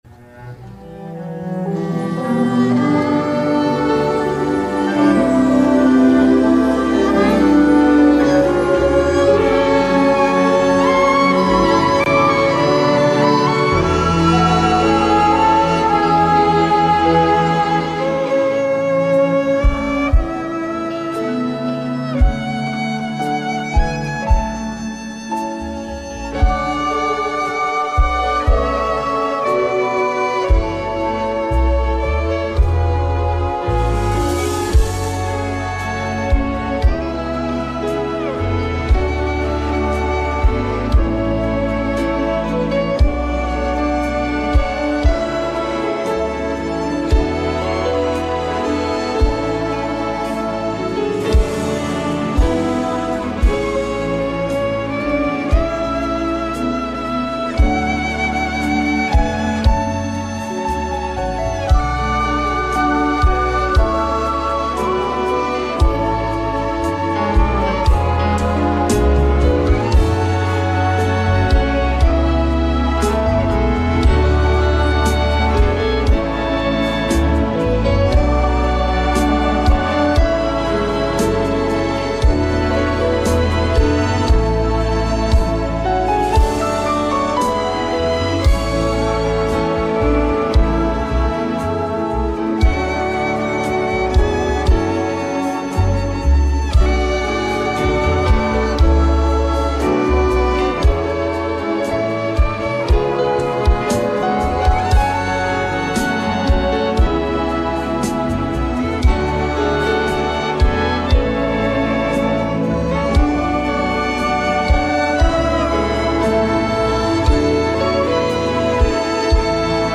DOWNLOAD THIS INSTRUMENTAL MP3